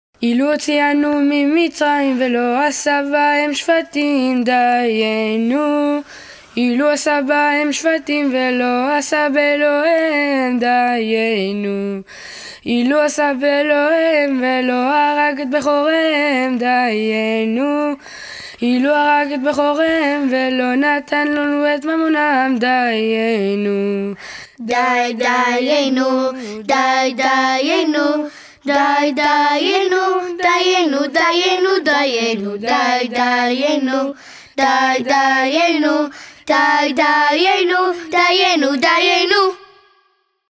Audio Enfants: